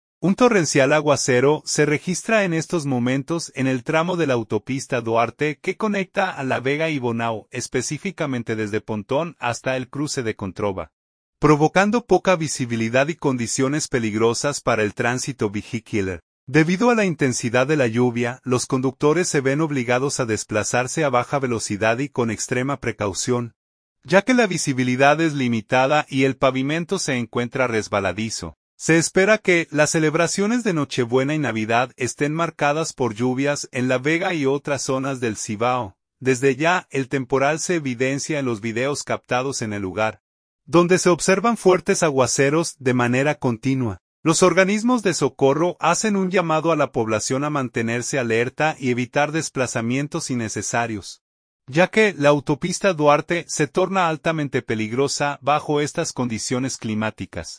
Desde ya, el temporal se evidencia en los videos captados en el lugar, donde se observan fuertes aguaceros de manera continua.